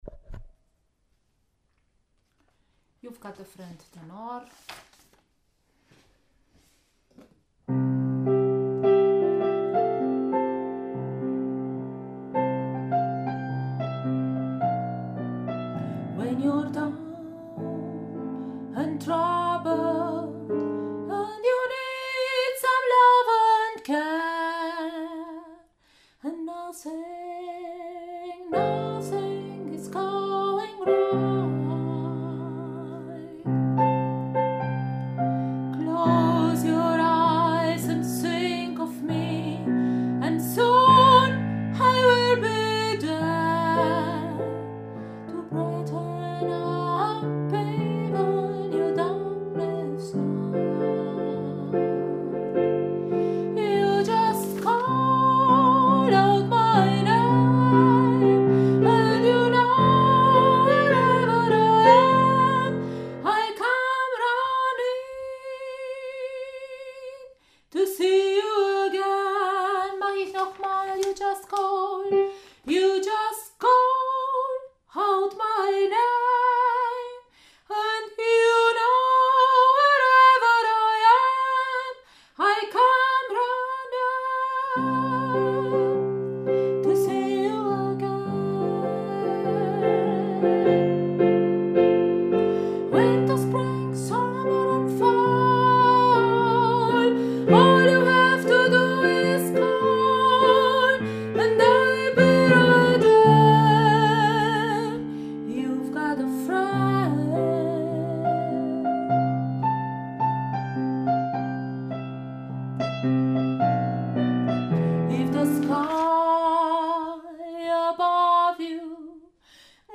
You’ve got a friend – Tenor
Tenor-Youve-got-a-friend.mp3